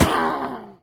death1.ogg